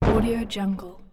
دانلود افکت صدای کوبیدن درب چوبی به هم 1
• کیفیت عالی: تمام صداها توی استودیوهای حرفه‌ای با بهترین تجهیزات ضبط شدن.
Sample rate 16-Bit Stereo, 44.1 kHz